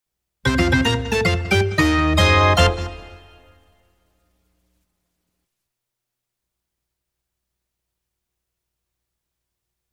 Звуки логотипа